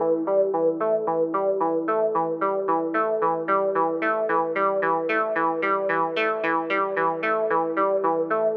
C - SynthRiff_syrup02.wav